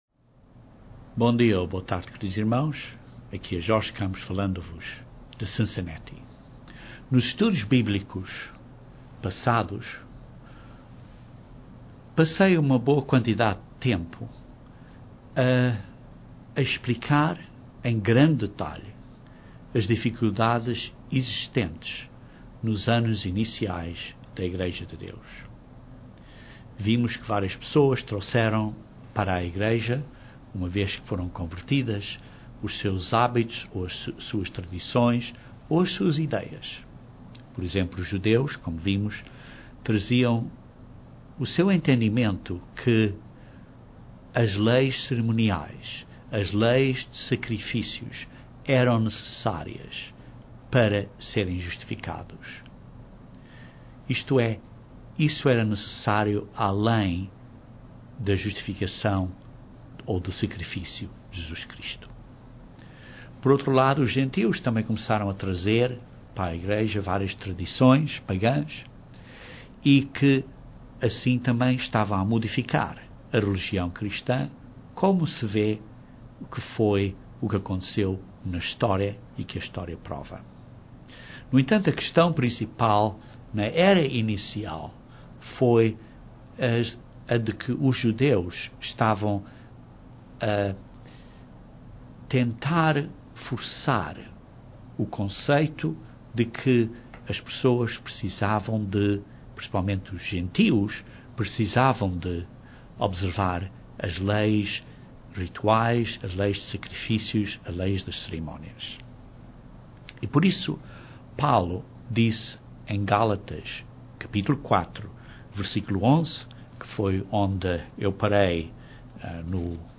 Este estudo Bíblico continua a explicação em grande detalhe da Epístola de Paulo aos Gálatas.